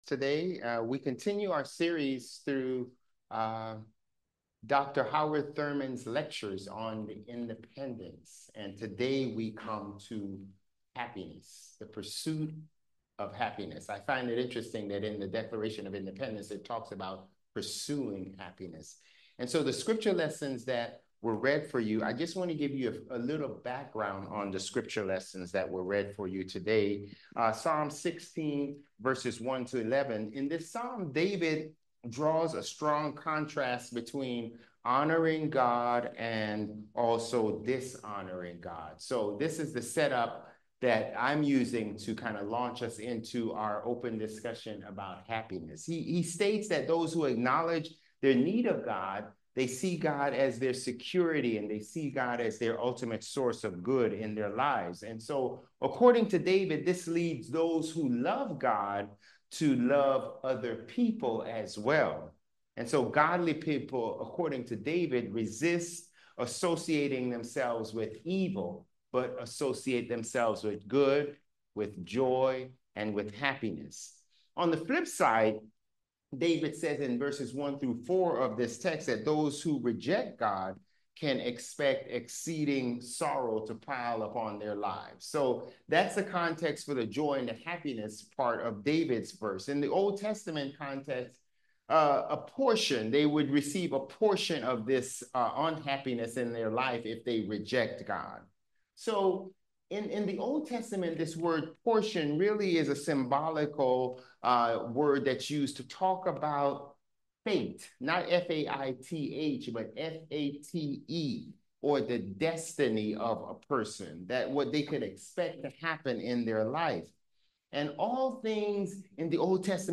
This sermon delves into the pursuit of happiness as mentioned in the Declaration of Independence, contrasting it with biblical perspectives from Psalm 16 and John 15. We examined Dr. Thurman's insights on happiness, discussing the relationship between inner and outer life, and the true nature of joy versus temporary happiness.